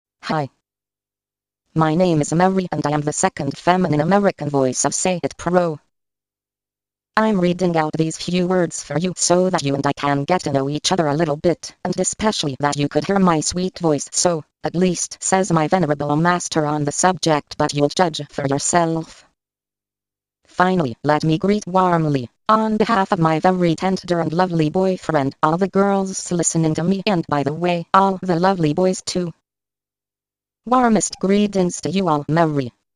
Texte de démonstration lu par Mary, deuxième voix féminine américaine de LogiSys SayItPro (Version 1.70)